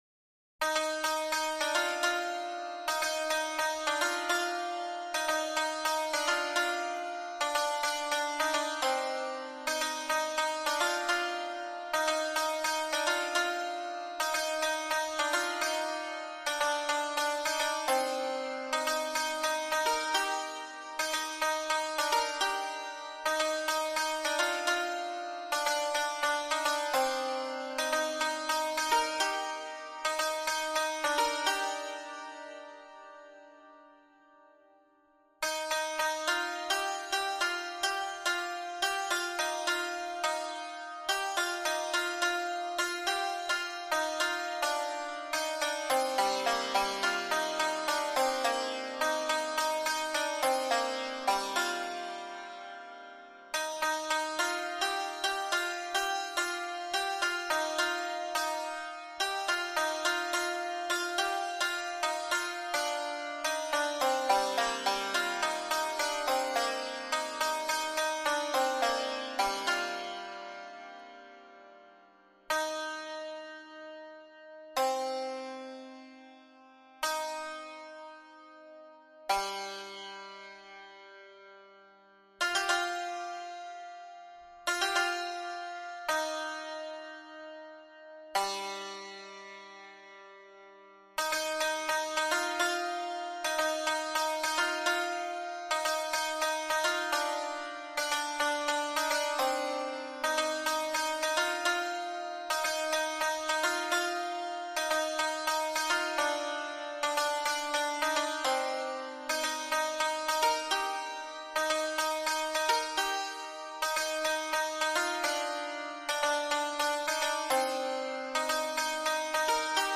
سطح : متوسط